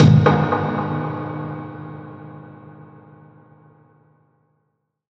Dark Piano 2.wav